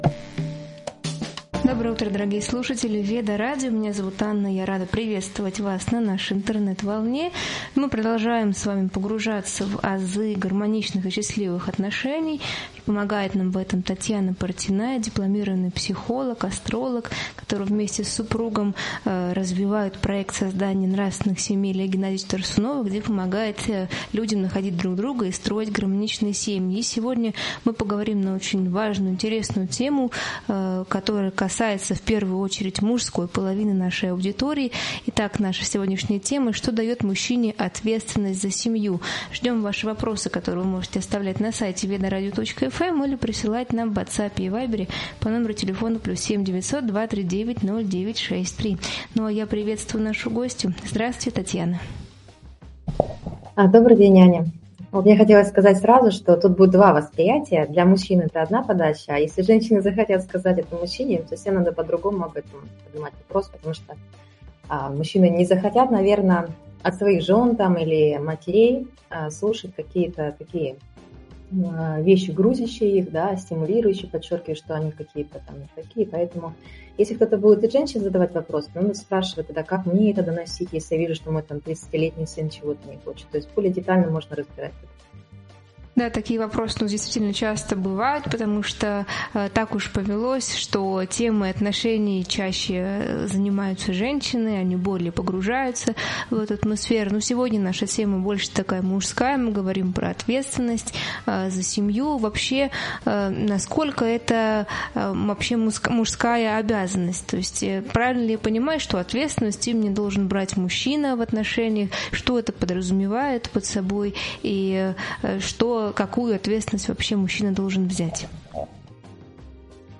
В эфире обсуждаются пути гармонии в семье и личной жизни: как мужчины и женщины взаимодействуют, учитывая свои природные различия, ответственность и эмоциональные потребности. Разбираются конфликты, влияние прошлого и кармы, воспитание детей без отца, смирение и открытое общение.